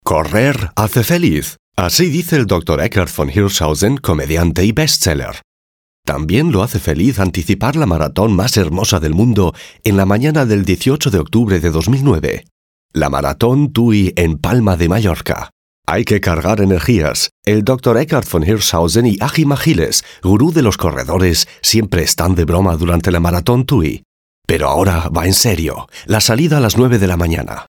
Volle, warme, präsente, moderative und rassige spanische Voice-Over-Stimme
kastilisch
Sprechprobe: Sonstiges (Muttersprache):
Experienced spanish Voice-Over Actor; Full, warm, prominent voice